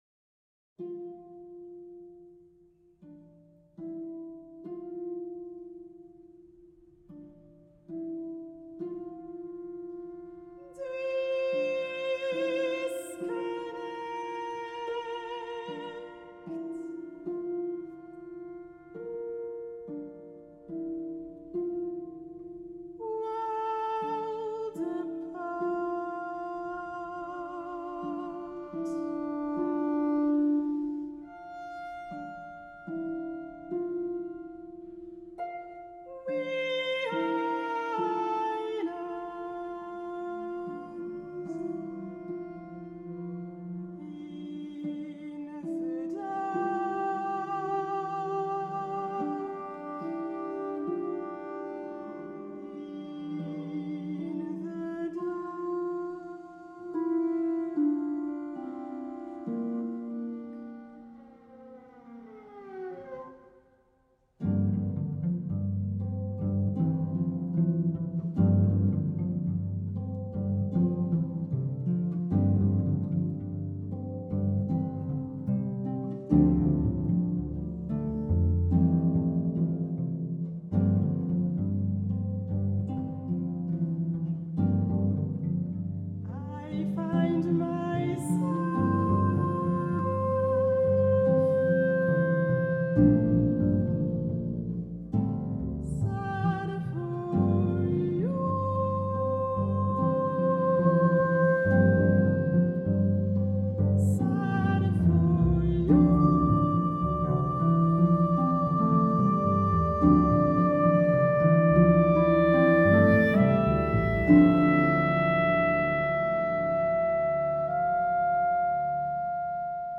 a British contemporary music ensemble